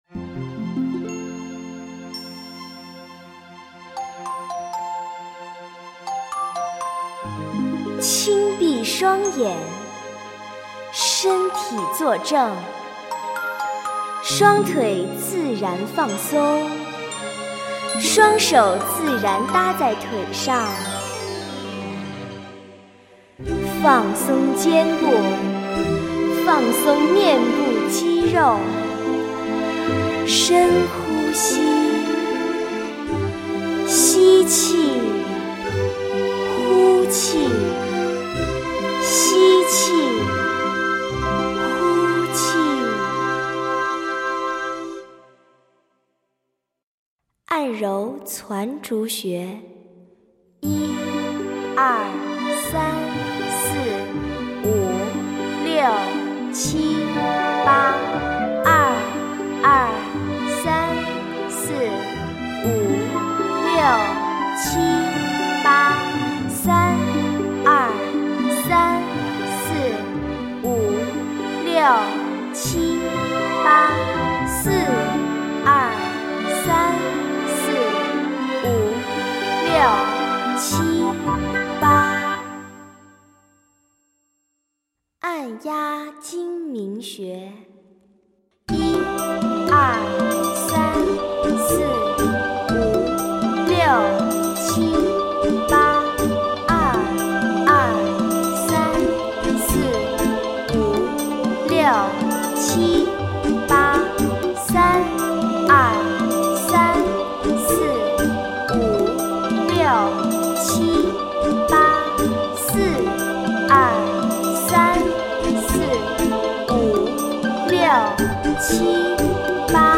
眼保健操2008修订版-有声读物9.20 .mp3